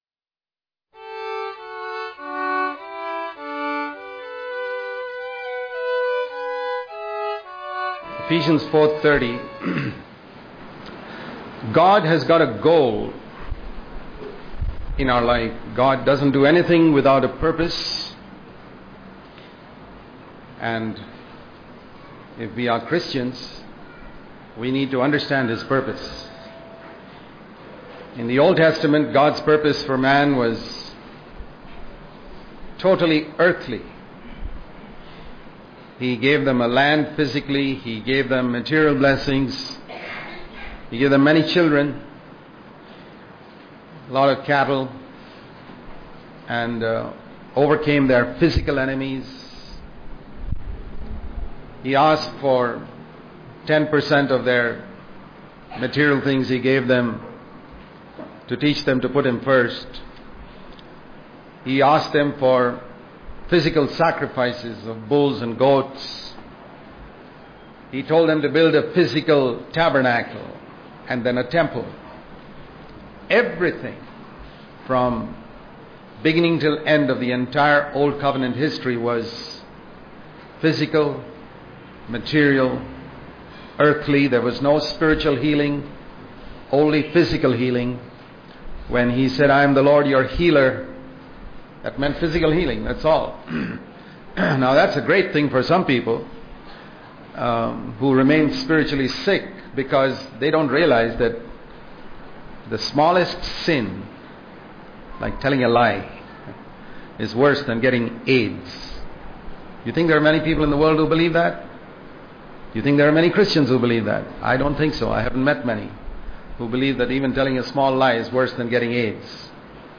May 19 | Daily Devotion | God's Goal For Man In The New Covenant Daily Devotion